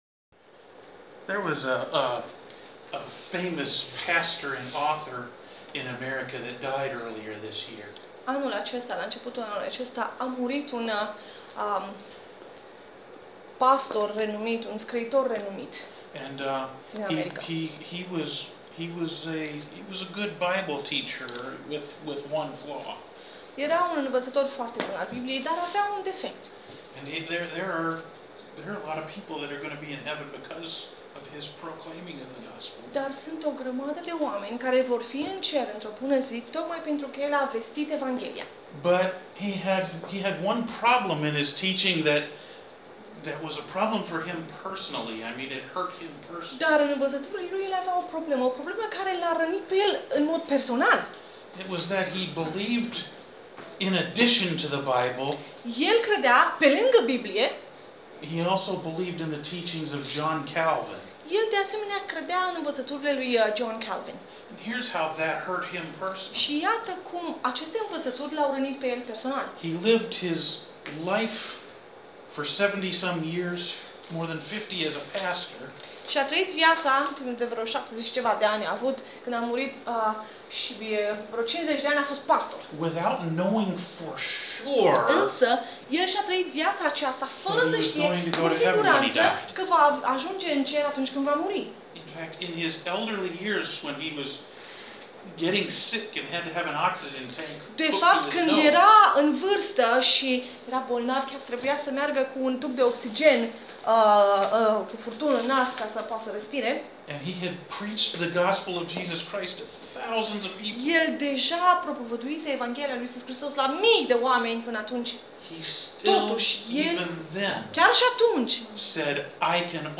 I – sermon audio | Biserica Harul Domnului